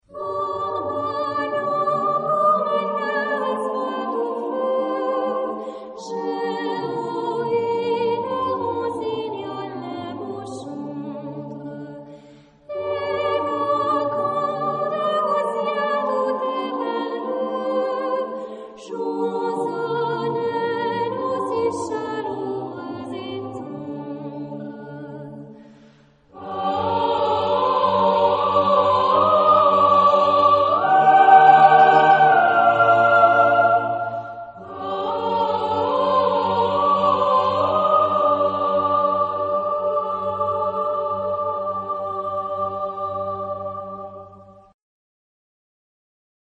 Genre-Style-Forme : Populaire ; Chœur ; Chanson ; Profane
Type de choeur : SATB  (4 voix mixtes )
Solistes : Soprano (1)  (1 soliste(s))
Tonalité : sol mineur